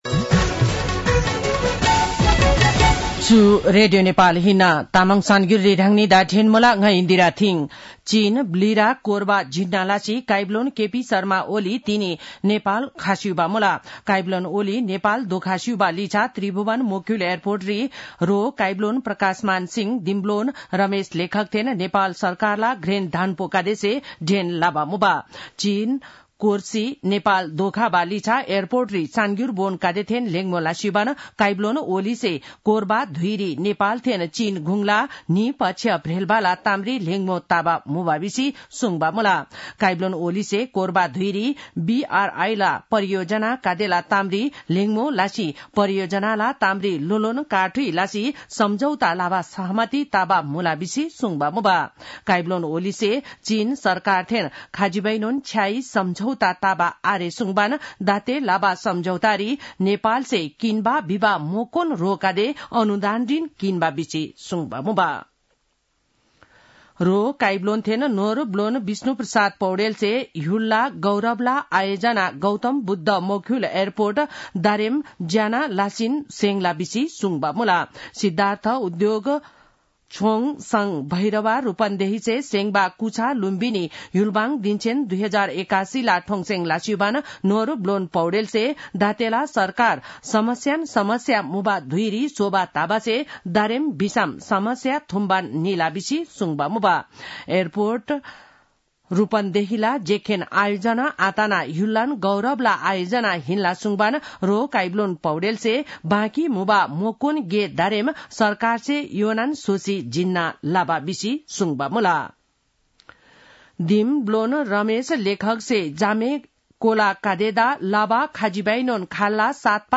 तामाङ भाषाको समाचार : २१ मंसिर , २०८१